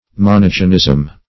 Meaning of monogenism. monogenism synonyms, pronunciation, spelling and more from Free Dictionary.
Search Result for " monogenism" : The Collaborative International Dictionary of English v.0.48: Monogenism \Mo*nog"e*nism\, n. (Anthropol.)